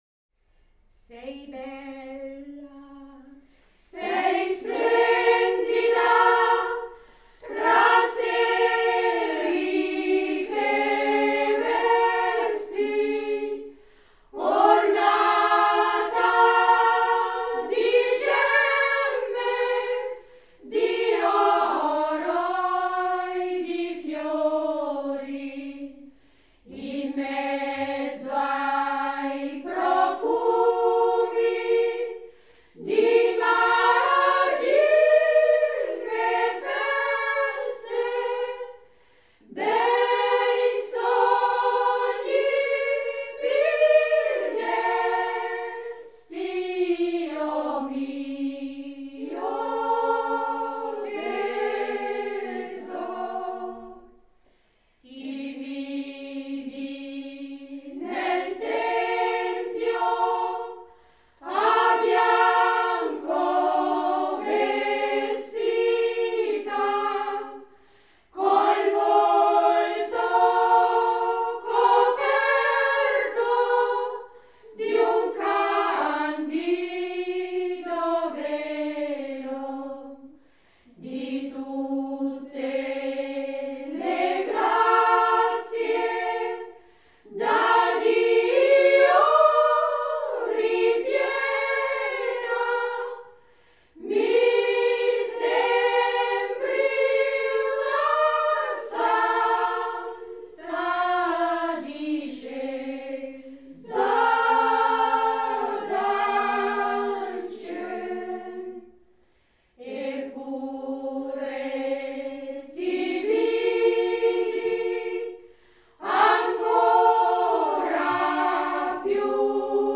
Ticino: Genuine Folk Music from Southern Switzerland
Gruppo spontaneo di Cavergno